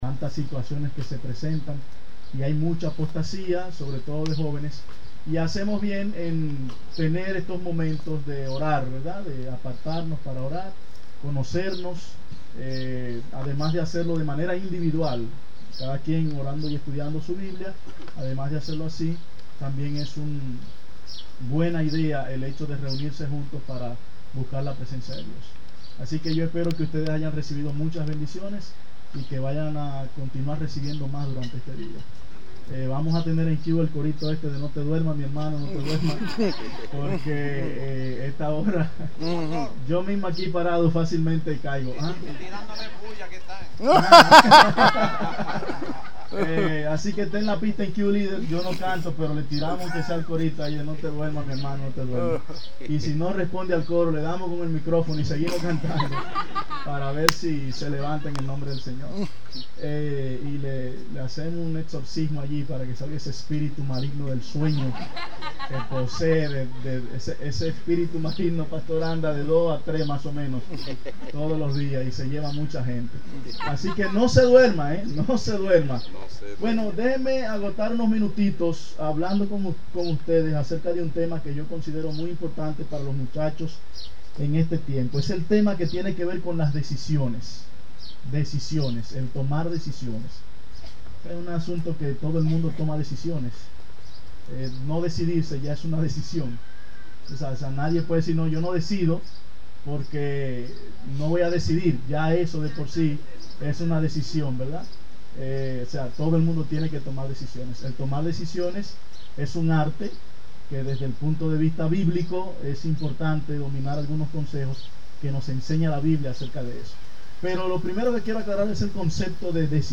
culto divino